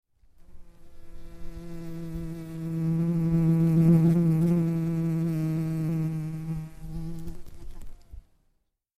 ape-mosca.mp3